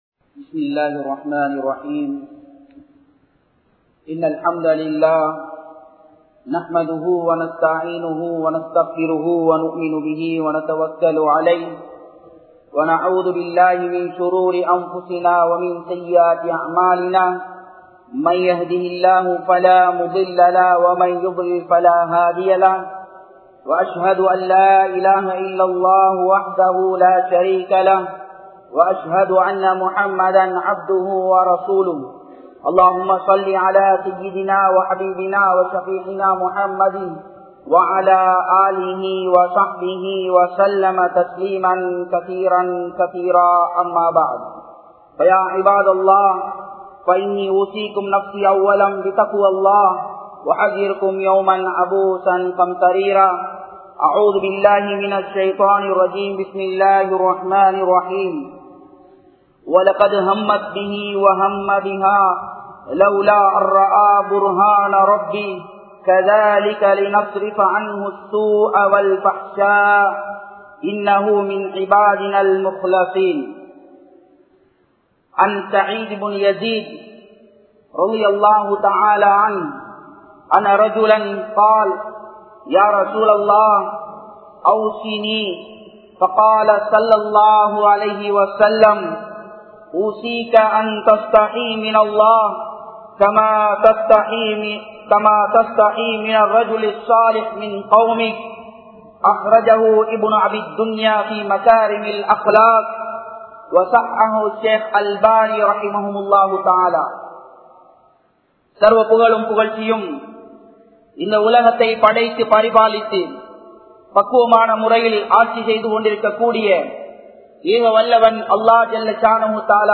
Islaathin Parvaiyil Kaathalar Thinam(இஸ்லாத்தின் பார்வையில் காதலர் தினம்) | Audio Bayans | All Ceylon Muslim Youth Community | Addalaichenai
Colombo 12, Aluthkade, Muhiyadeen Jumua Masjidh